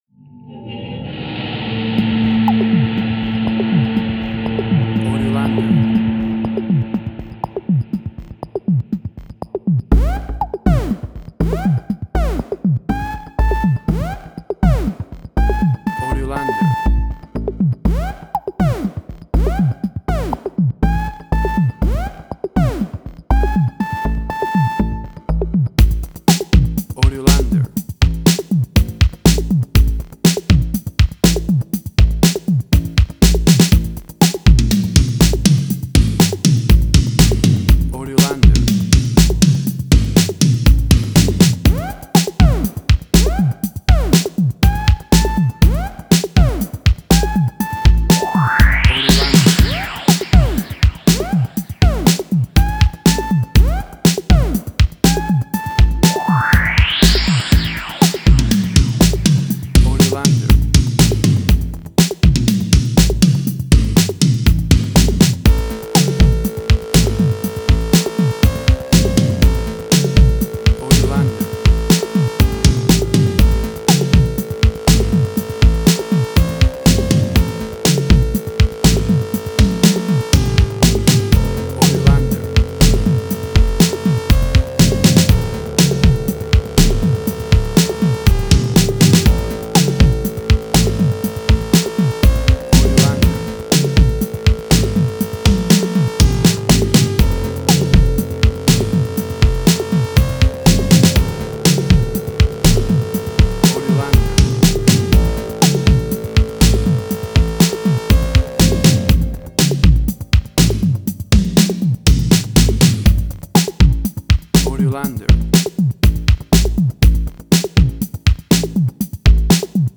Future Retro Wave
New Wave.
WAV Sample Rate: 16-Bit stereo, 44.1 kHz
Tempo (BPM): 121